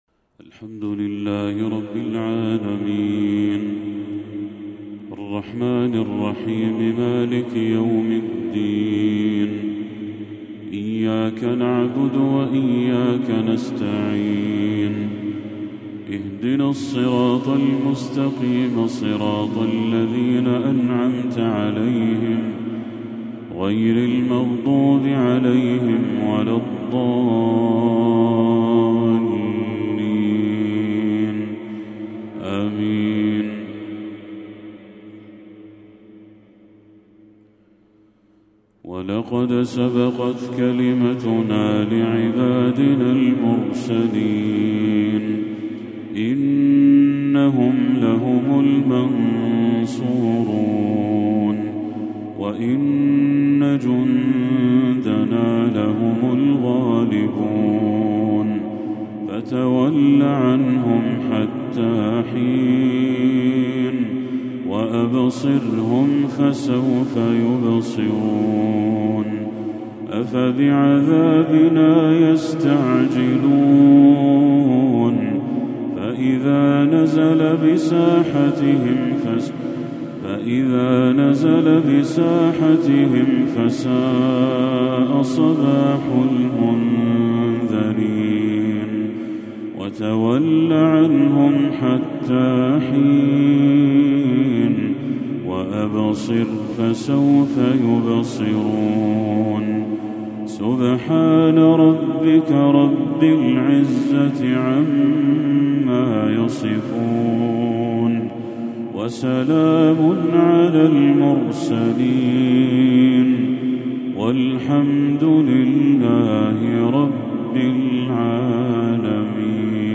تلاوة رائعة لخواتيم سورتي الصافات والحديد للشيخ بدر التركي | مغرب 13 ربيع الأول 1446هـ > 1446هـ > تلاوات الشيخ بدر التركي > المزيد - تلاوات الحرمين